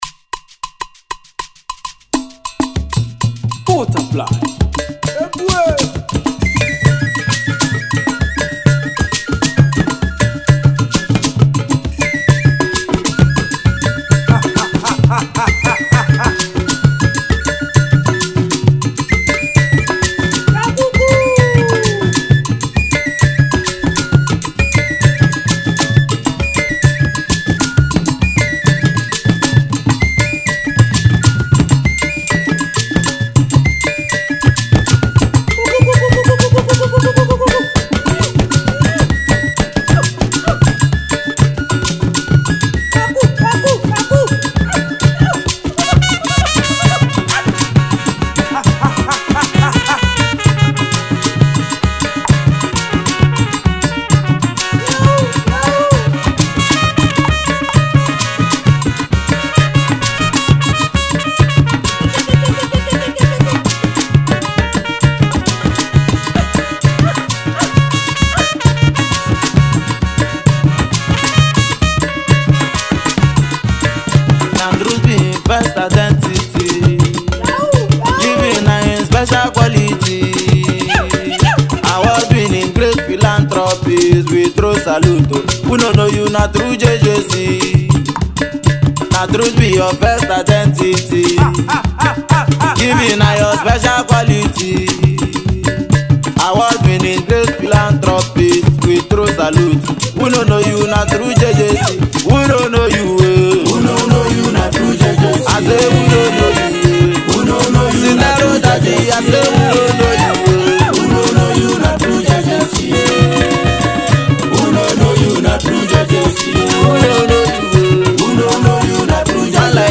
live African traditional instruments (Egwu, oghele)